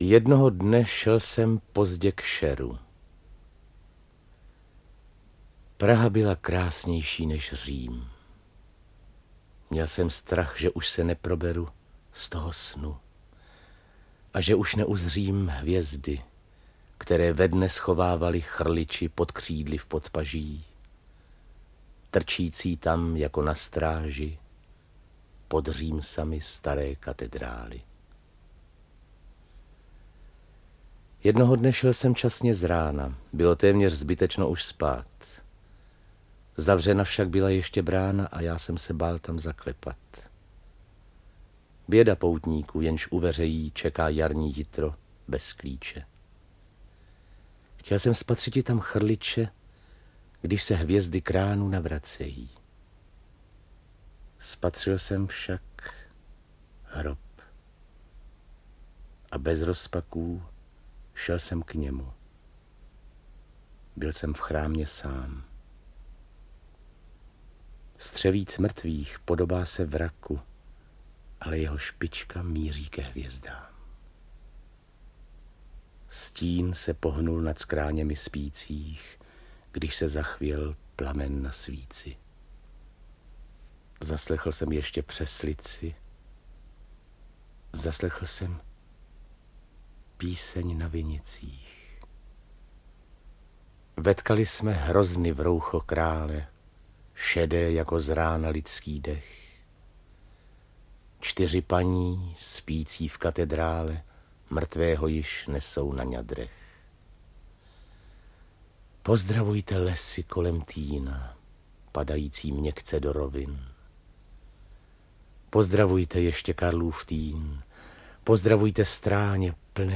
Interpret:  Václav Voska
Tuto audioknihu určitě doporučuji k poslechu.